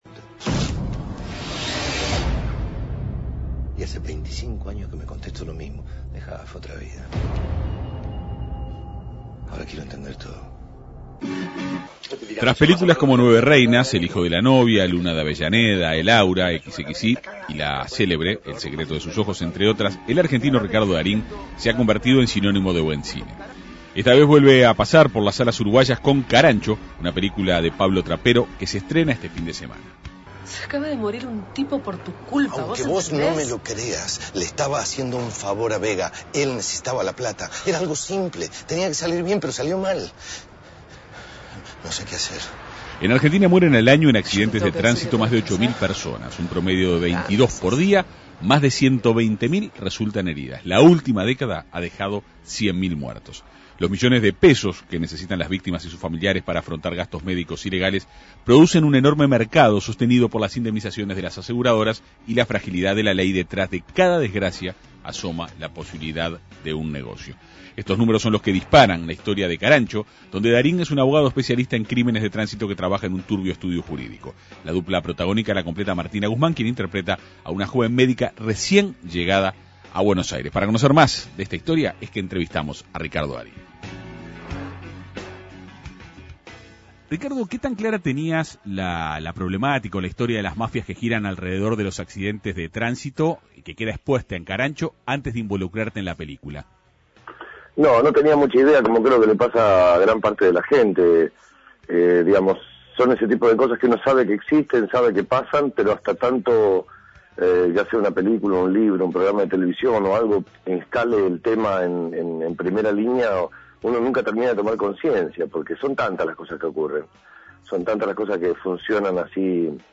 Esta vez, vuelve a pasar por las salas uruguayas con Carancho, una película de Pablo Trapero que se estrena este viernes. El actor conversó en la Segunda Mañana de En Perspectiva.